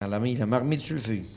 Locutions vernaculaires
Catégorie Locution